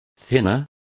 Complete with pronunciation of the translation of thinners.